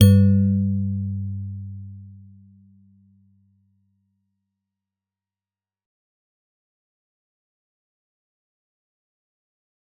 G_Musicbox-G2-f.wav